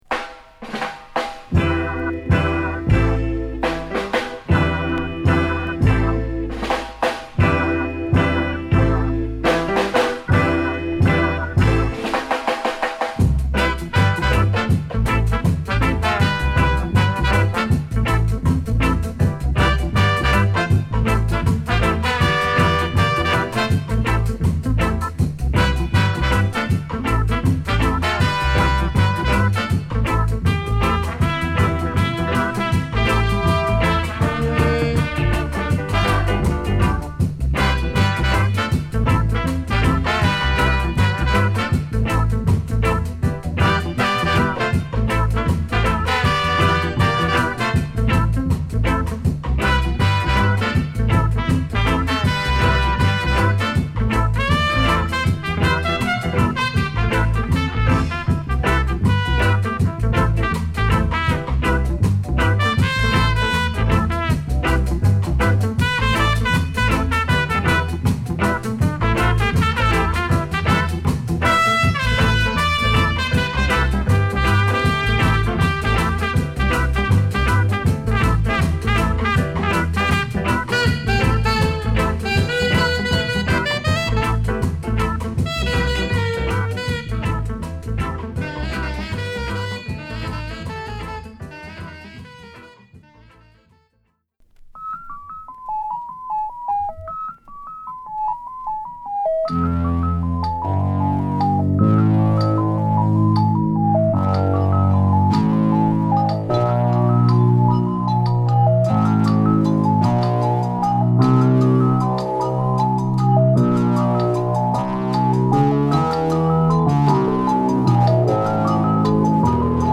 Soul / Funk / Jazz